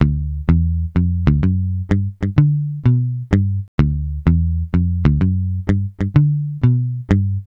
Swingerz 3 Bass-D.wav